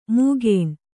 ♪ mūgēṇ